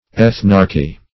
Search Result for " ethnarchy" : The Collaborative International Dictionary of English v.0.48: Ethnarchy \Eth"narch*y\n. [Gr. ?.] The dominion of an ethnarch; principality and rule.